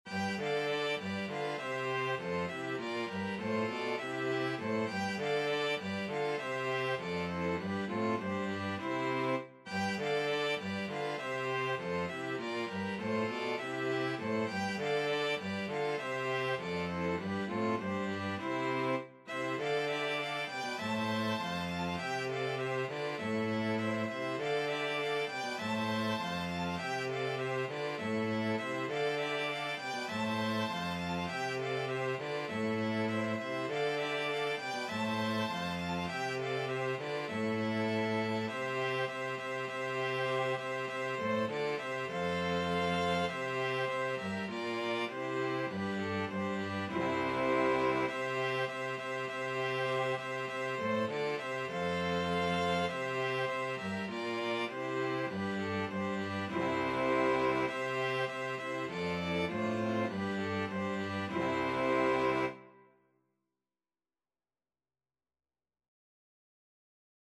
Free Sheet music for String Quartet
Violin 1Violin 2ViolaCello
C major (Sounding Pitch) (View more C major Music for String Quartet )
4/4 (View more 4/4 Music)
Classical (View more Classical String Quartet Music)